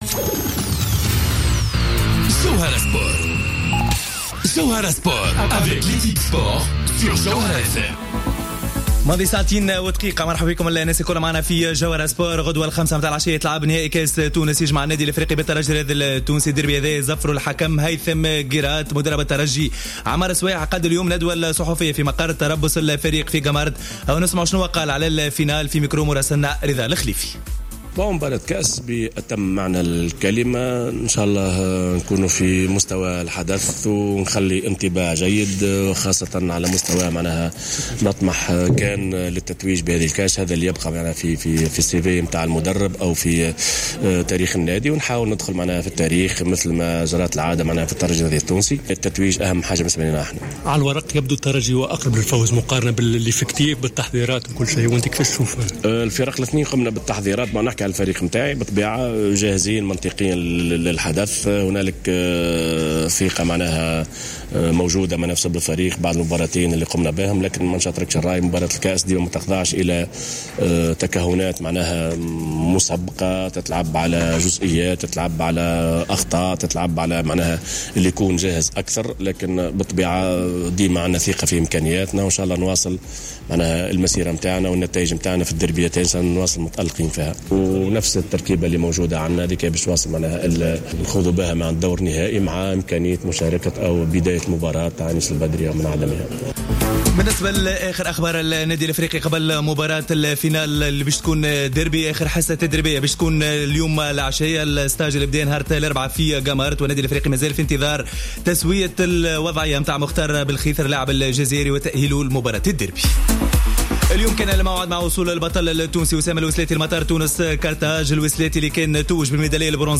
ندوة صحفية للترجي الرياضي التونسي